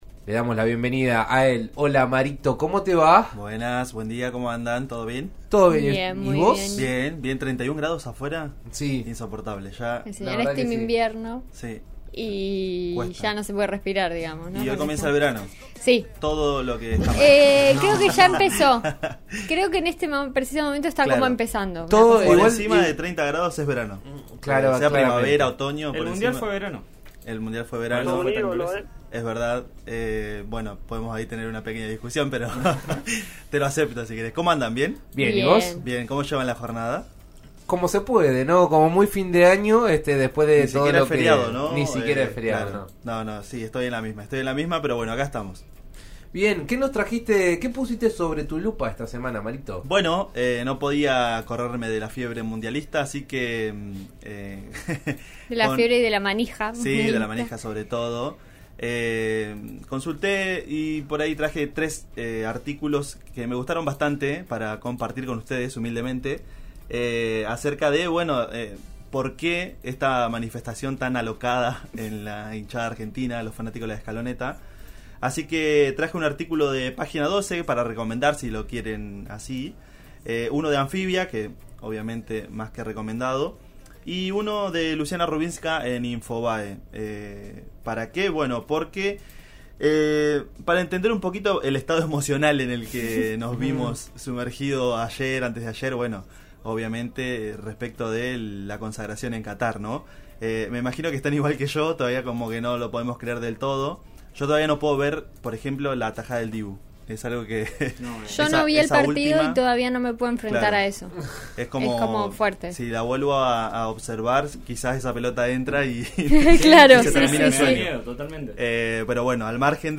En La Lupa, la columna radial de En Eso Estamos, conversamos sobre el tema.
En La Lupa, la columna de En Eso Estamos de RN Radio, conversamos sobre el tema.